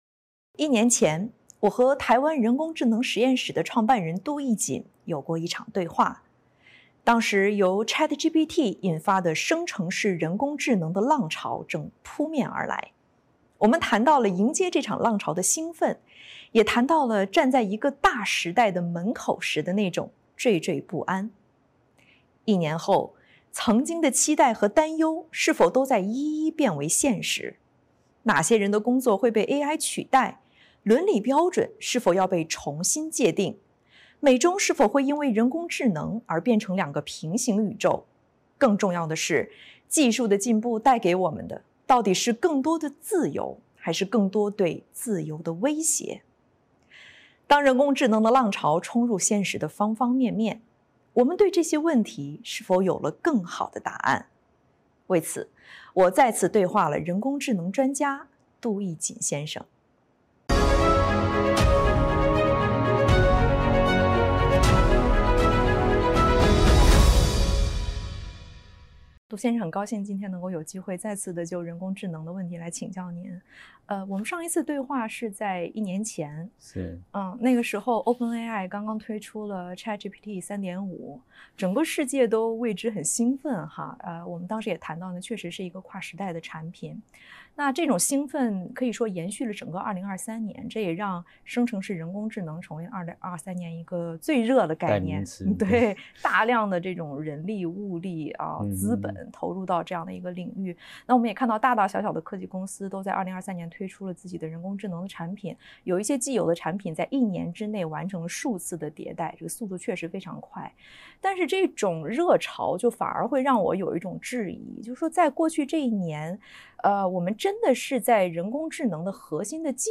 《纵深视角》节目进行一系列人物专访，受访者所发表的评论不代表美国之音的立场。